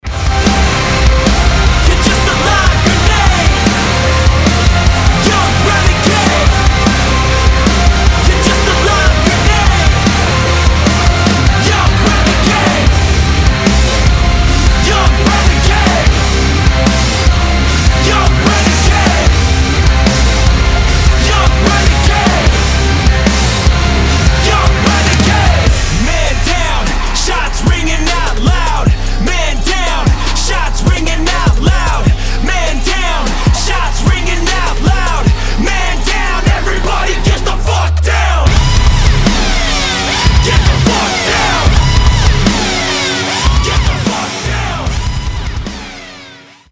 мужской вокал
громкие
брутальные
Rap-rock
Alternative Rock
Alternative Rap
Rap Core